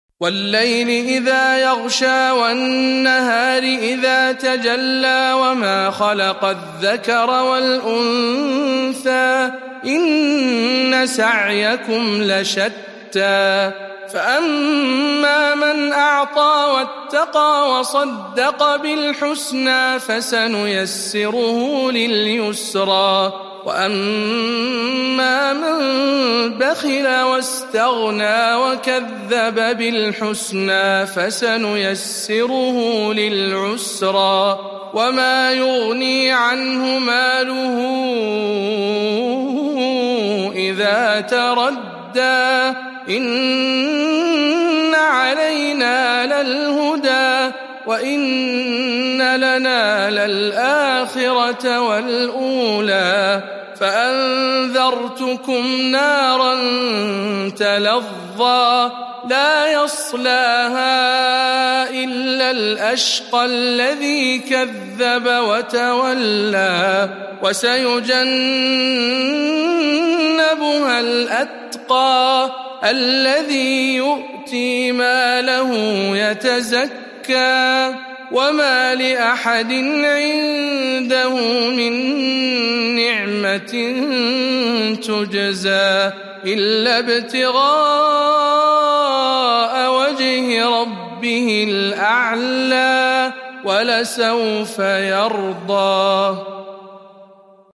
سورة الليل برواية شعبة عن عاصم